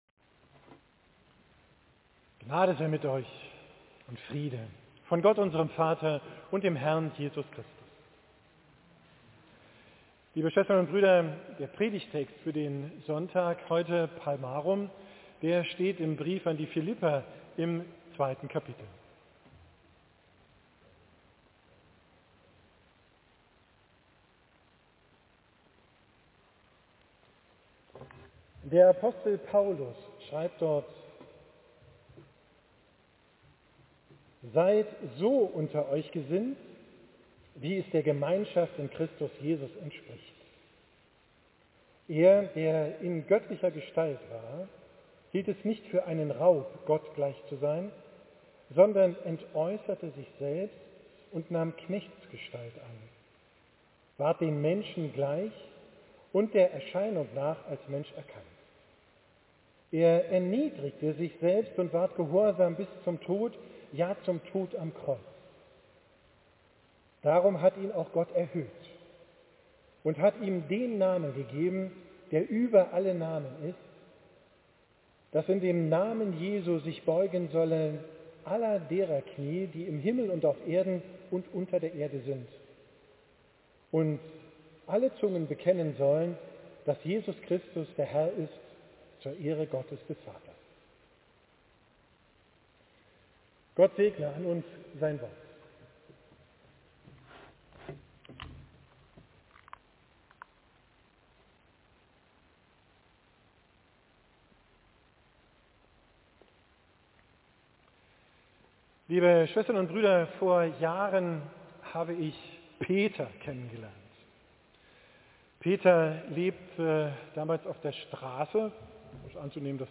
Predigt vom Sonntag Palmarum, 24.